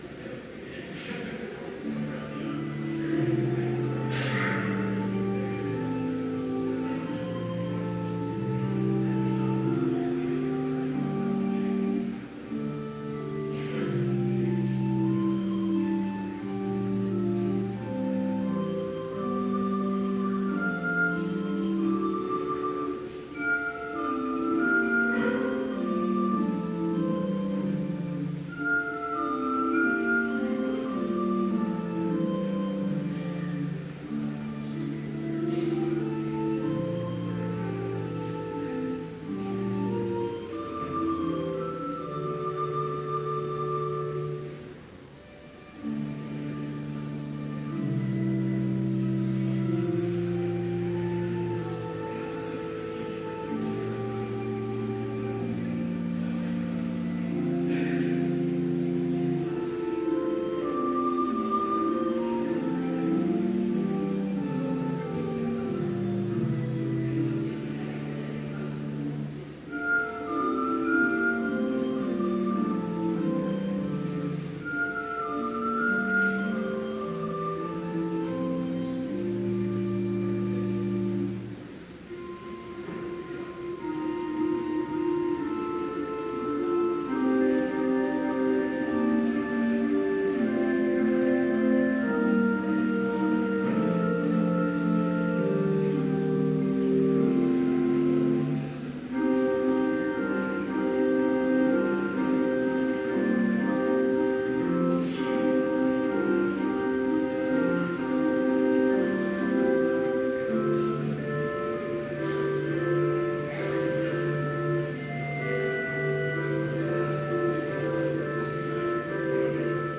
Complete service audio for Chapel - December 11, 2017